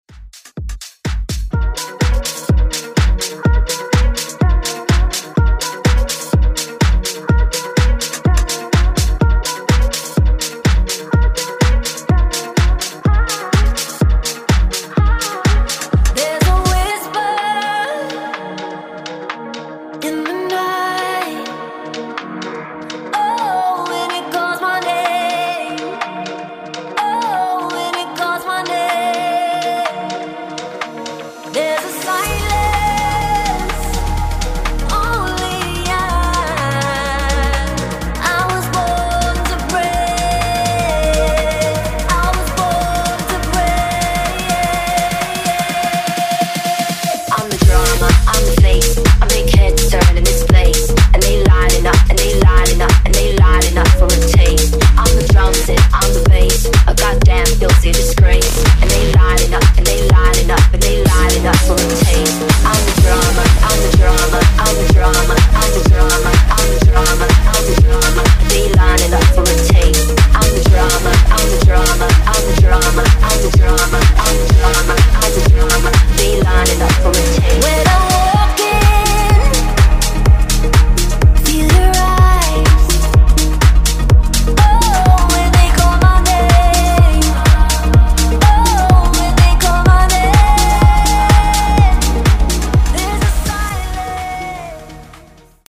Genre: HIPHOP
Dirty BPM: 90 Time